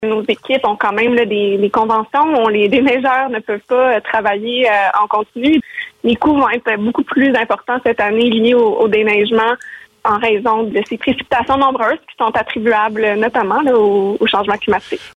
De passage sur nos ondes ce mercredi, elle a précisé que la plupart des postes encore vacants à la Ville sont ceux liés aux travaux publics.